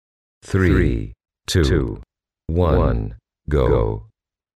Звуки таймера